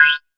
078 CR78 Beat.wav